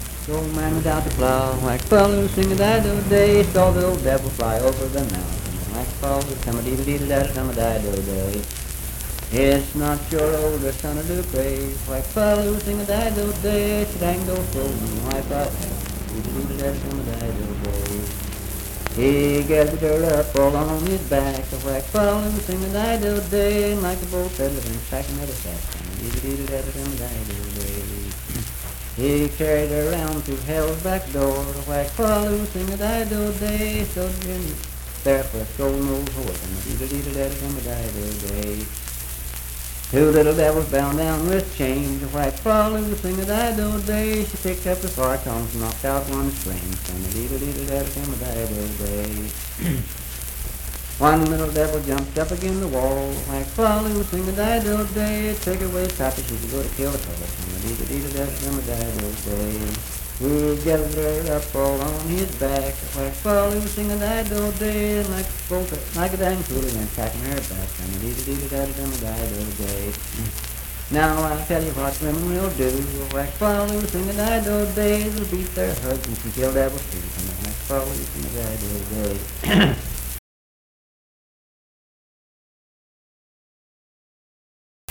Unaccompanied vocal music performance
Voice (sung)
Calhoun County (W. Va.)